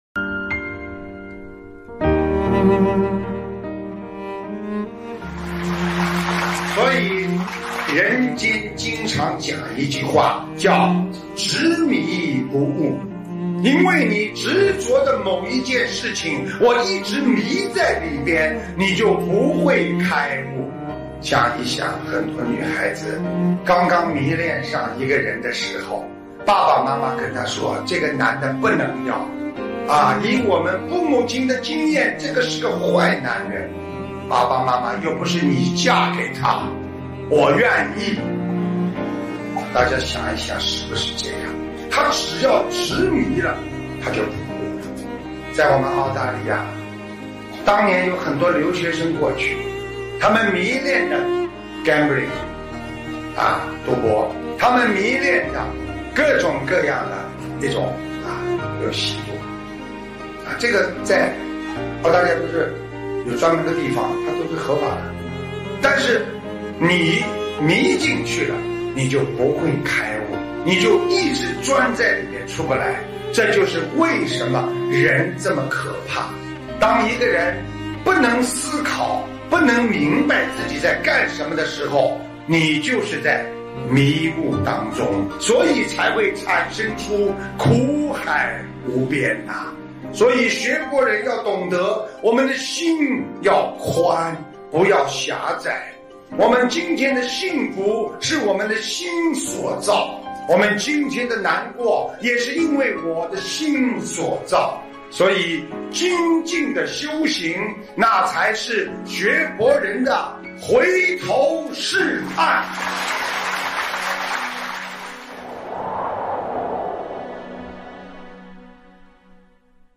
—— 2014年9月13日 台湾法会开示